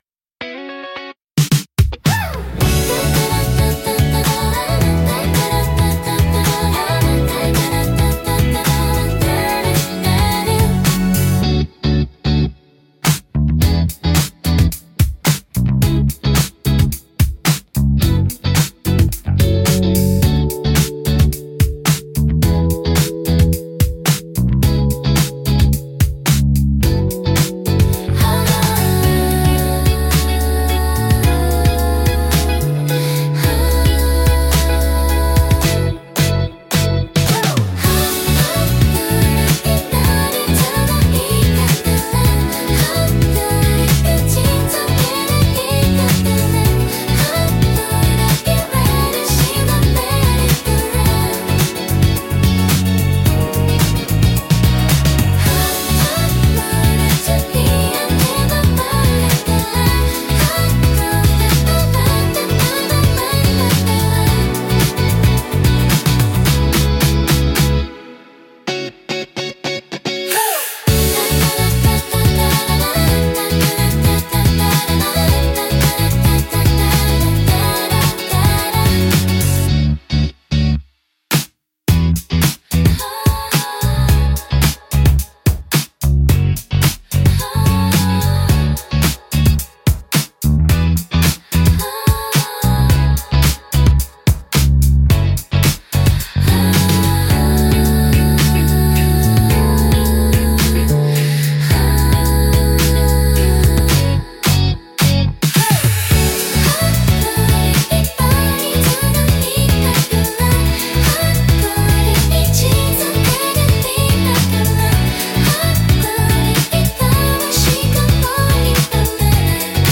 シティポップは、1970～80年代の日本で生まれたポップスの一ジャンルで、都会的で洗練されたサウンドが特徴です。
聴く人にノスタルジックかつモダンな気分を届けるジャンルです。